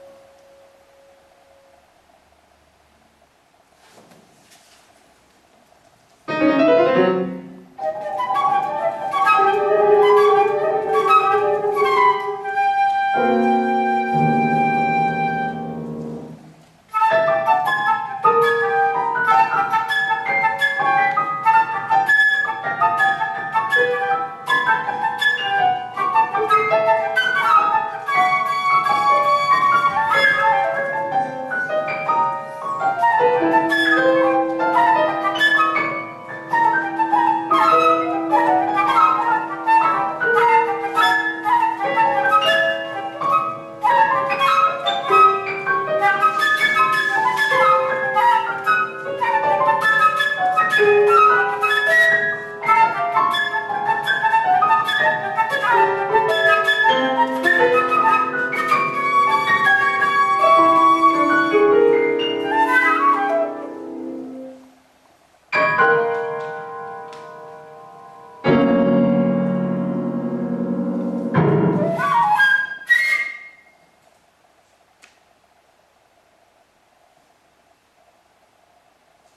Flötenmusik